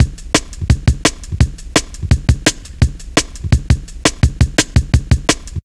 Index of /90_sSampleCDs/Zero-G - Total Drum Bass/Drumloops - 3/track 57 (170bpm)